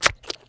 gibhit1.wav